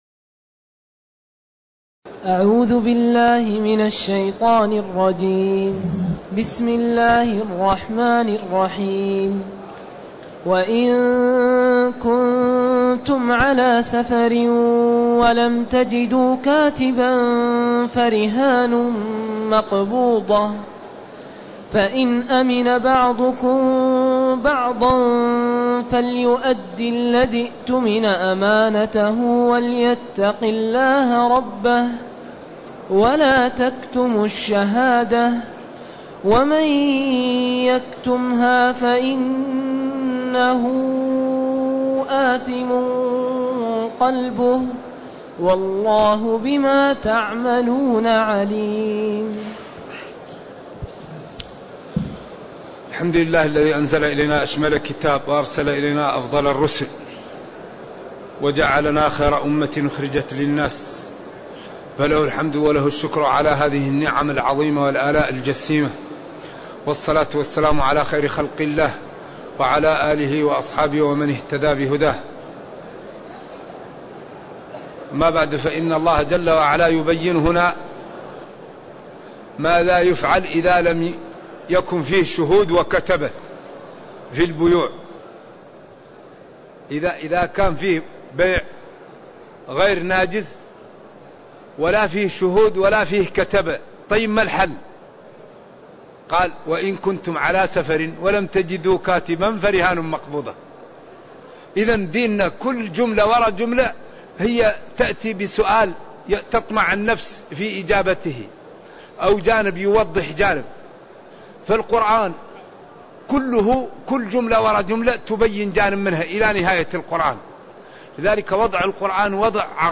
تاريخ النشر ٣ ذو الحجة ١٤٢٨ هـ المكان: المسجد النبوي الشيخ